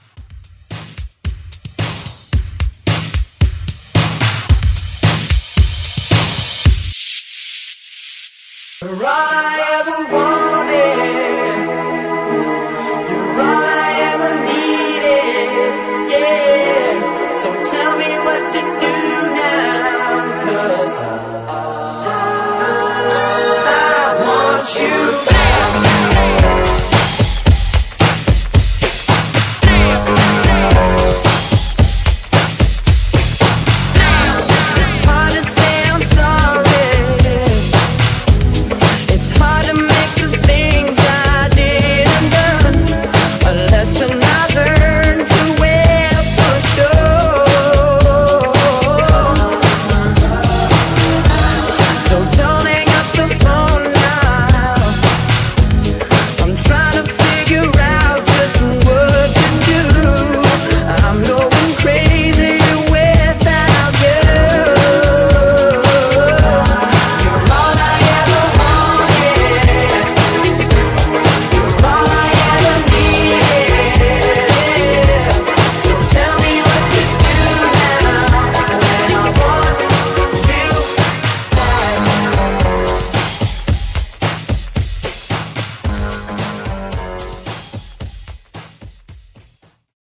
90s R&B Redrum